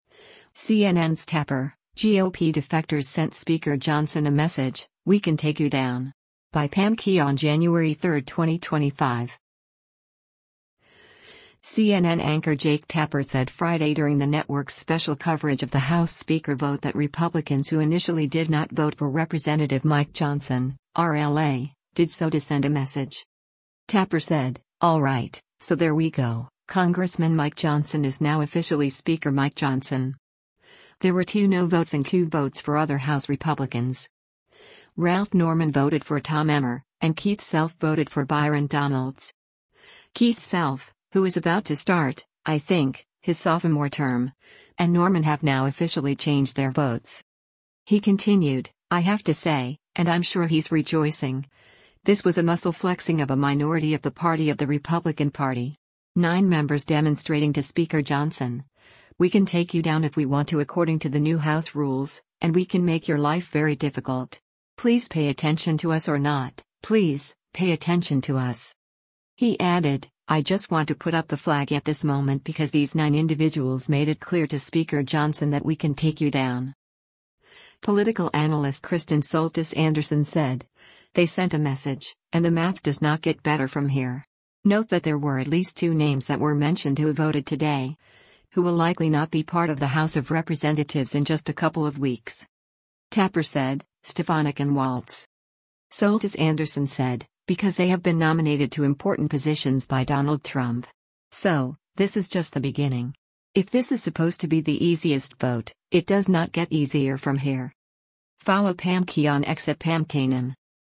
CNN anchor Jake Tapper said Friday during the network’s special coverage of the House Speaker vote that Republicans who initially did not vote for Rep. Mike Johnson (R-LA) did so to send a message.